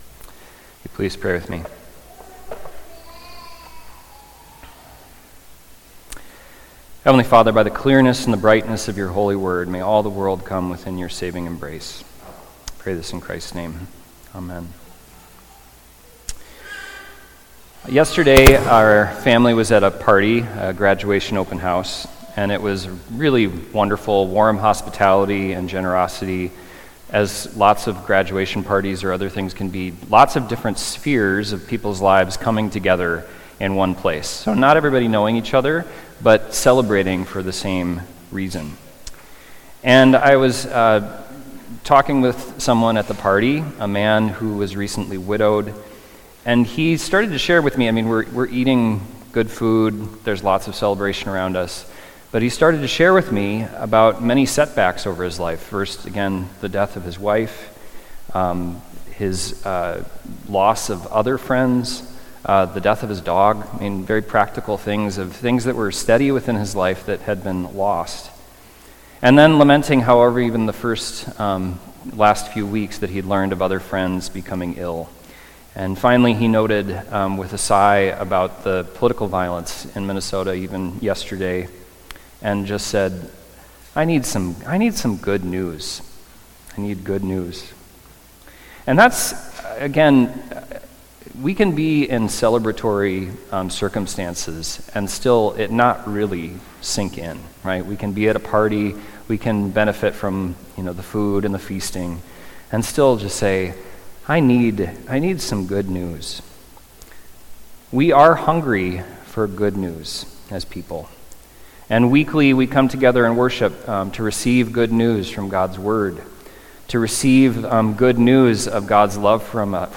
Sunday Worship–June 15, 2025
Sermons